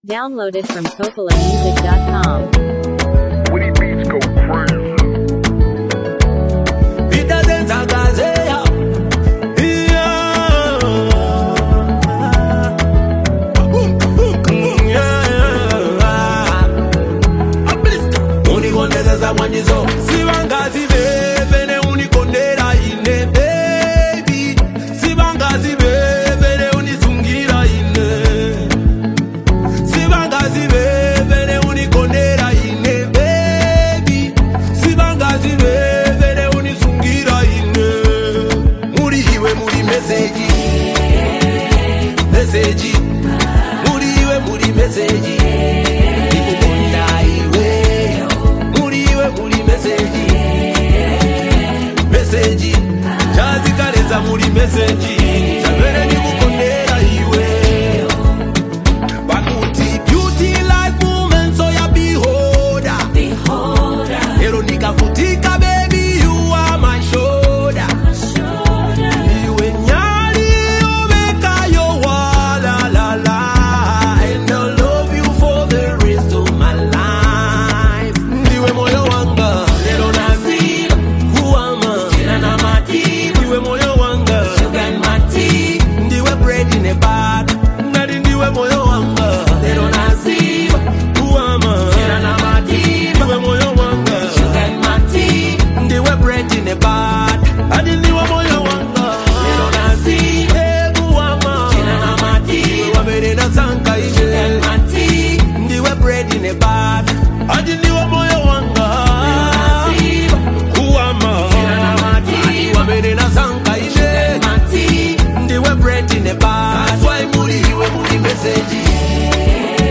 is a powerful and emotionally charged song
voice carries both strength and vulnerability